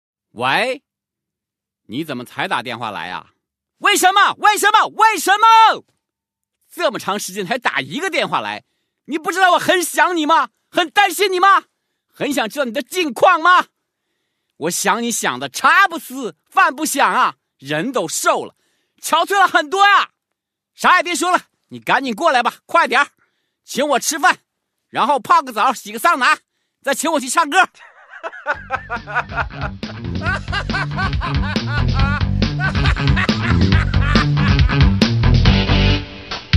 M4R铃声, MP3铃声, 搞笑铃声 115 首发日期：2018-05-14 13:24 星期一